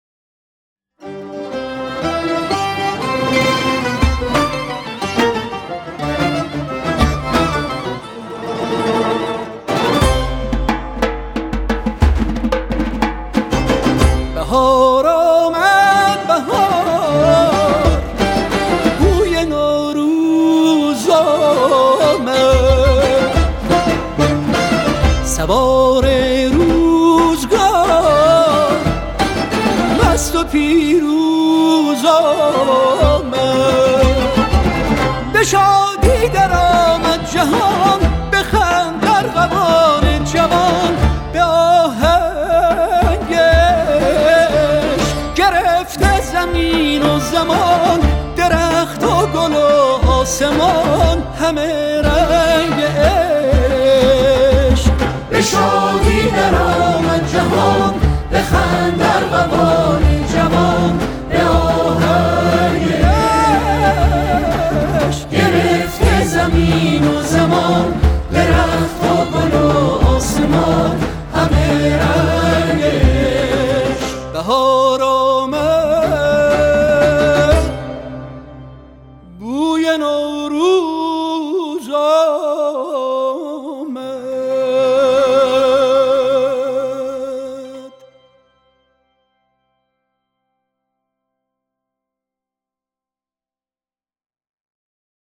سنتور
کمانچه
ضرب زورخانه